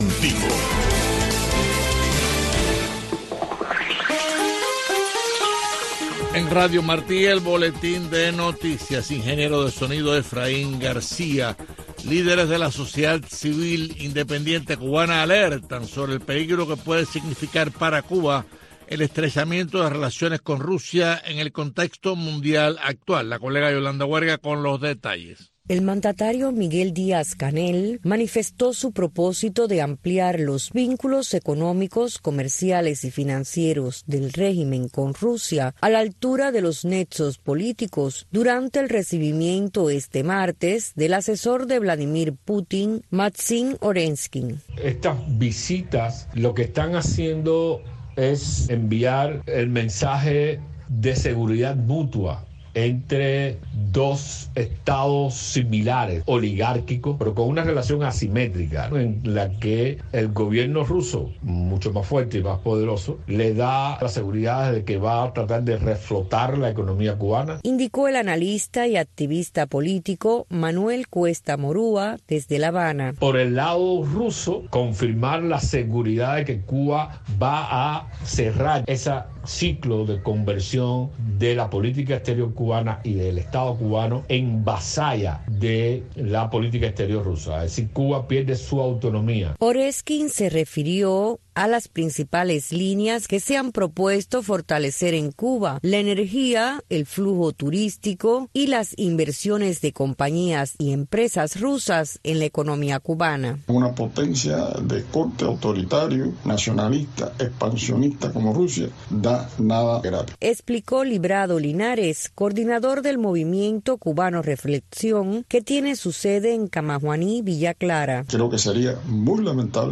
Una mirada a la situación migratoria, para analizar las leyes estadounidenses, conversar con abogados y protagonistas de este andar en busca de libertades y nuevas oportunidades para lograr una migración ordenada y segura.